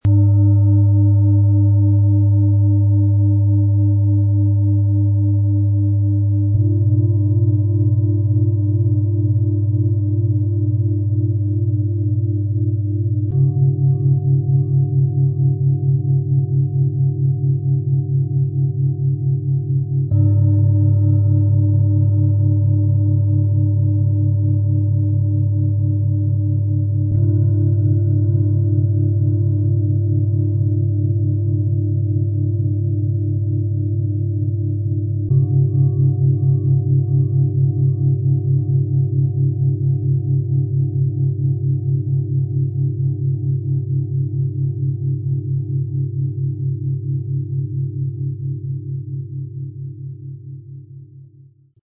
Erdung, Wärme & Klarheit - Klangschalenmassage - Set aus 3 Klangschalen, Ø 21,2 - 26,1 cm, 4,03 kg
Im Sound-Player - Jetzt reinhören können Sie den Original-Ton genau dieser Schalen anhören und ihre harmonischen Schwingungen erleben.
Die größte Schale – Tiefer, erdender Klang
Die mittlere Schale – Harmonisch & wärmend
• Klangmeditation & Raumklang: Die Schalen füllen den Raum mit harmonischen Schwingungen und schaffen eine beruhigende Atmosphäre.
MaterialBronze